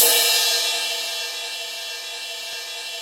Index of /90_sSampleCDs/Roland - Rhythm Section/CYM_Crashes 1/CYM_Crsh Modules